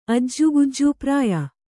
♪ ajjugujju prāya